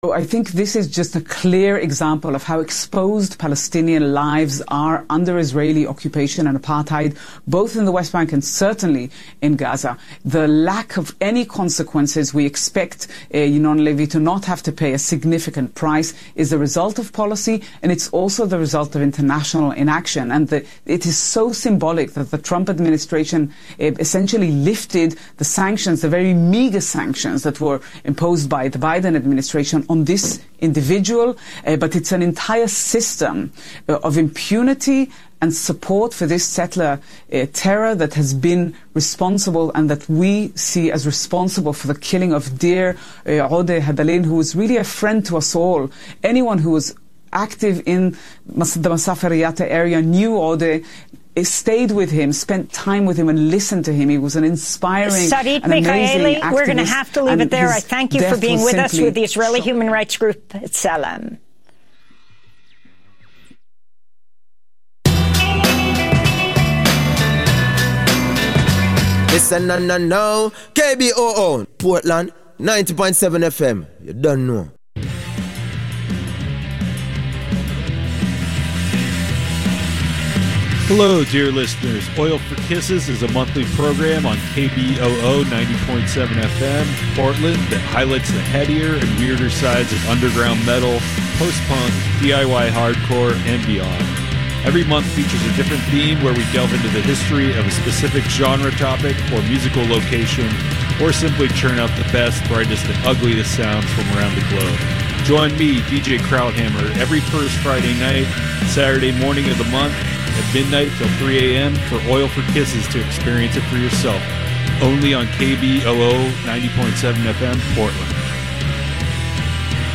Hosted by: KBOO News Team
Non-corporate, community-powered, local, national and international news